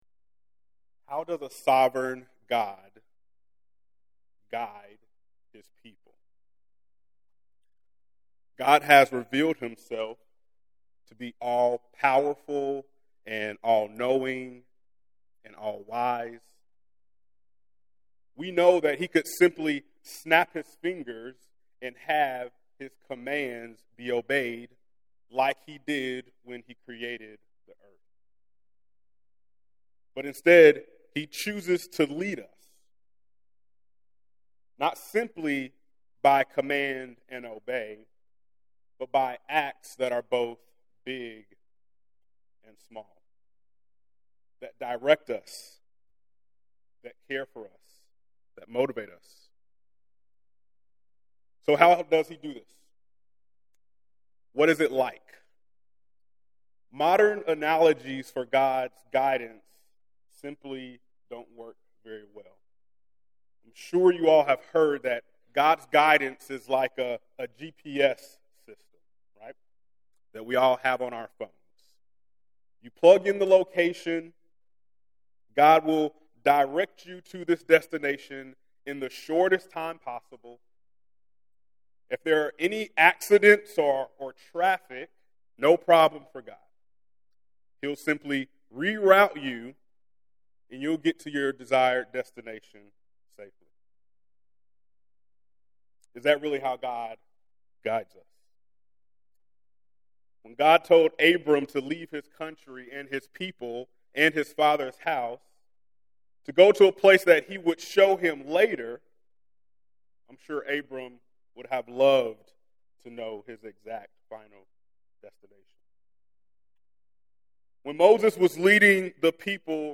preaching